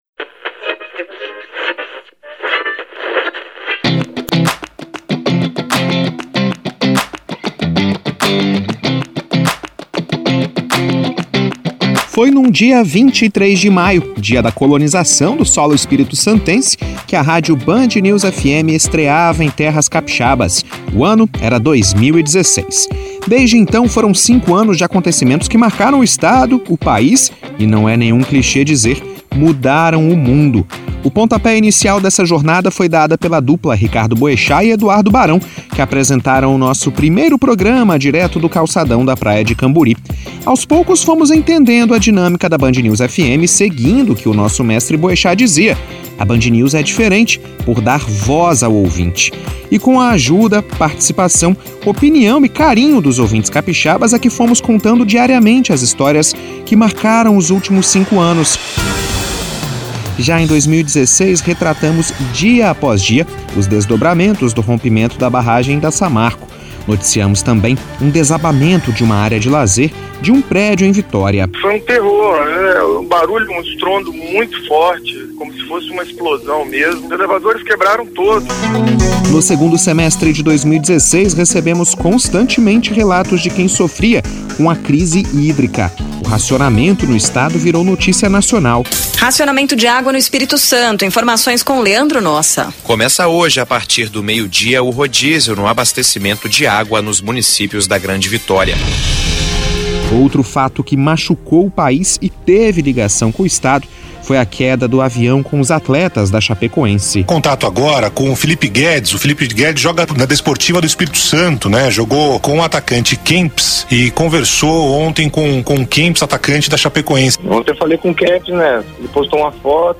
ESPECIAL-5-ANOS-1ª-MATÉRIA.mp3